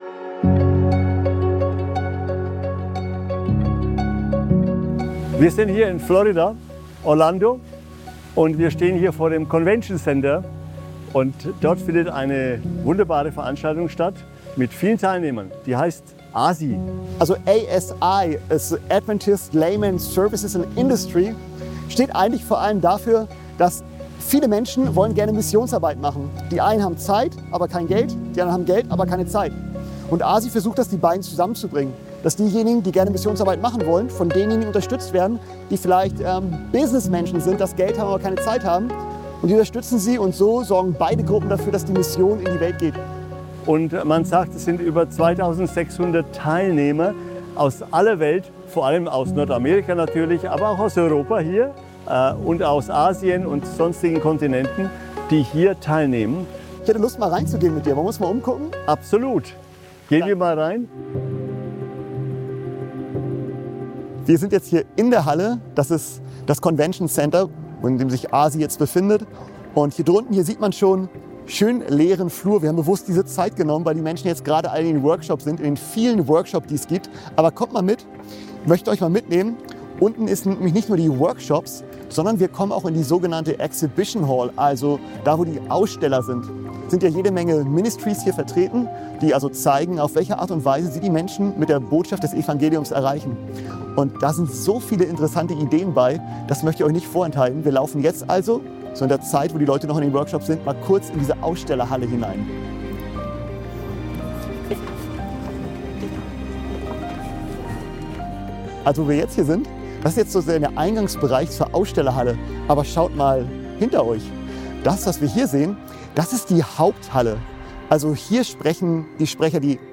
Über 2.600 Teilnehmer aus aller Welt - Unternehmer, Missionare und Gläubige vereint an einem Ort! Wir nehmen dich mit zur ASI-Konferenz in Orlando